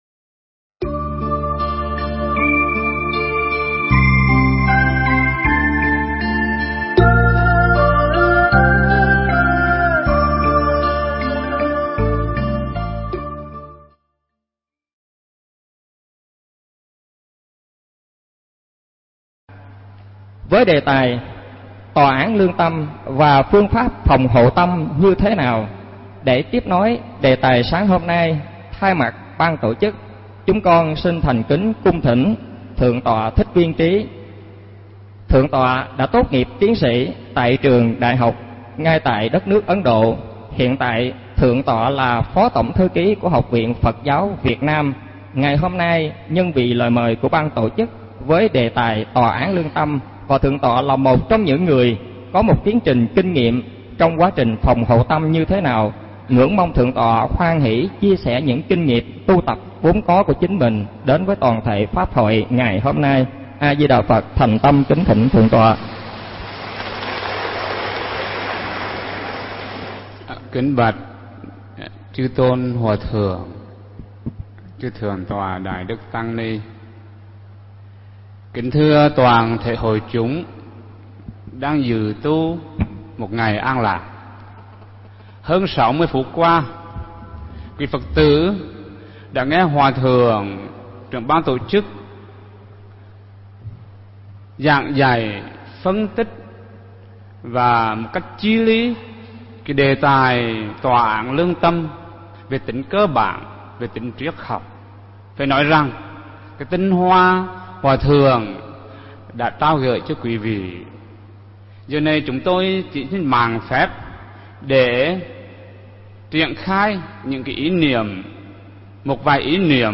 Mp3 thuyết pháp
trong khoá tu Một Ngày An Lạc lần 40 tại chùa Phổ Quang (tân Bình, Tp.HCM)